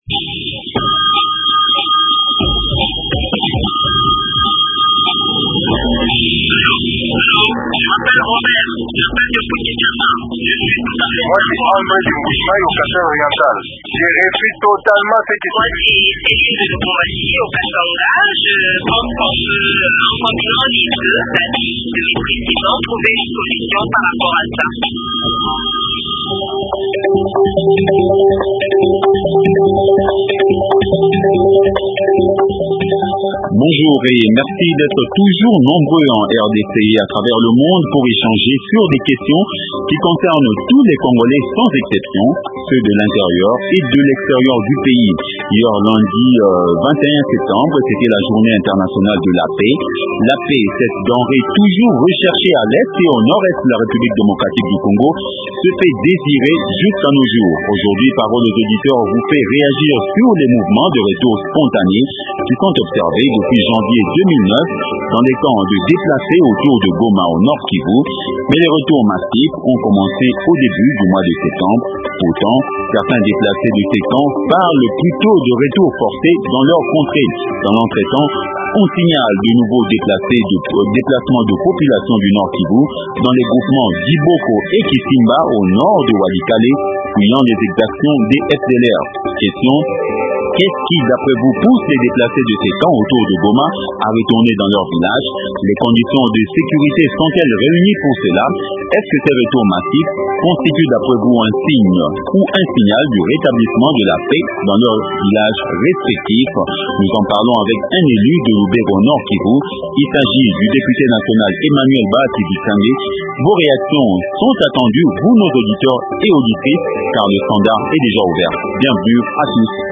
Honorable Emmanuel Bahati Vitsange, député national, élu du territoire de Luberorn